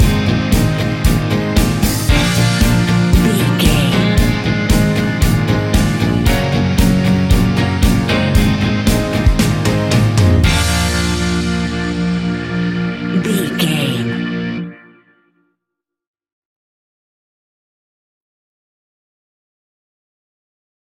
Fast paced
Mixolydian
B♭
pop rock
energetic
uplifting
acoustic guitars
drums
bass guitar
electric guitar
piano
organ